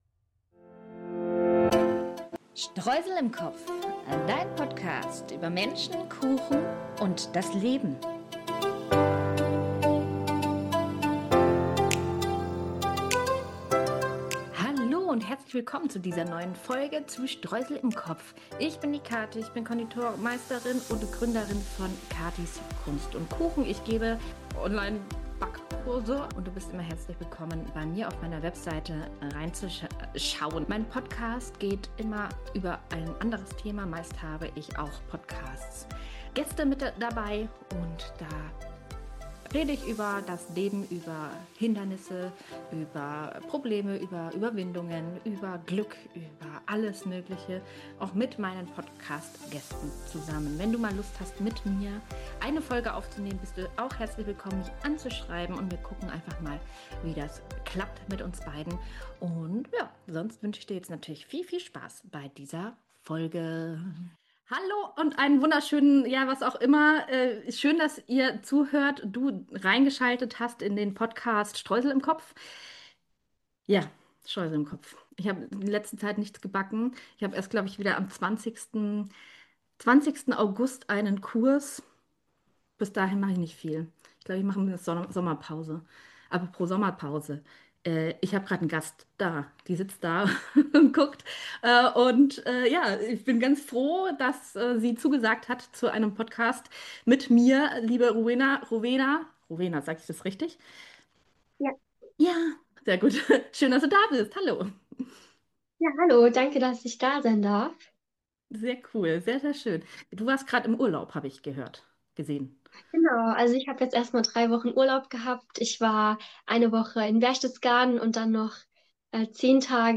Aber: Dieses Interview haben wir vor dem Finale aufgenommen.